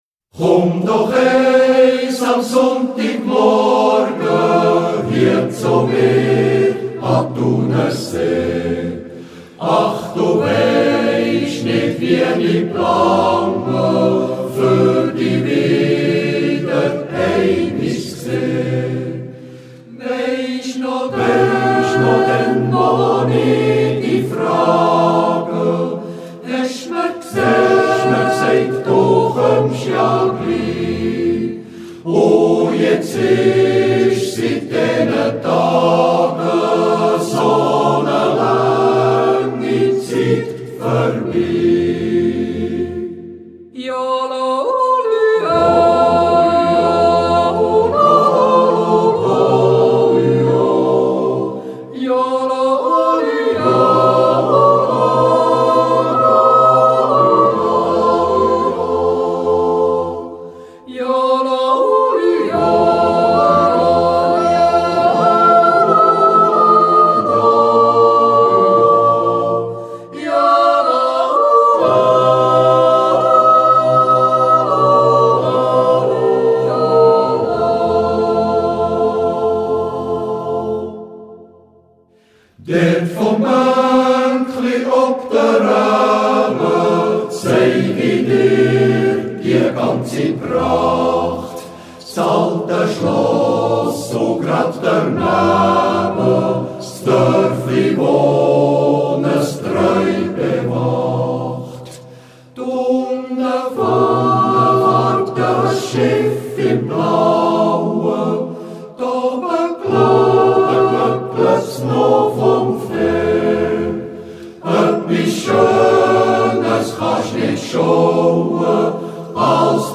Jodel song.